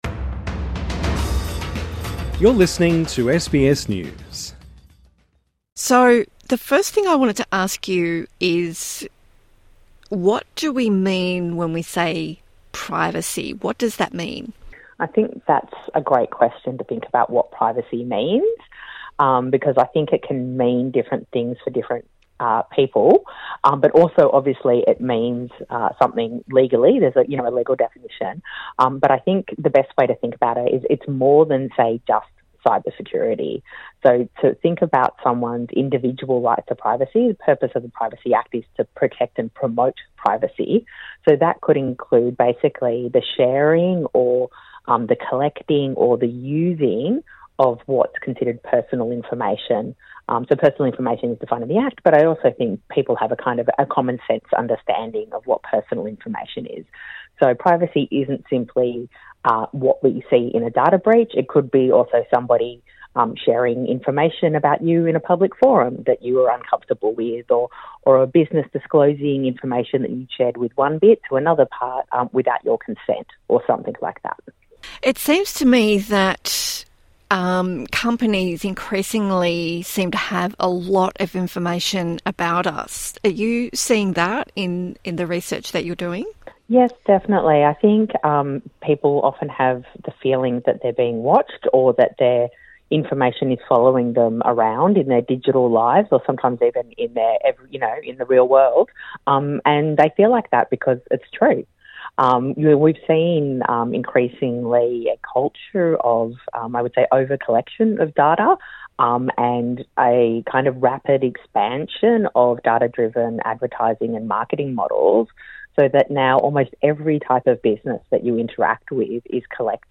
INTERVIEW: What is the 'privacy pub test' and why do we need one?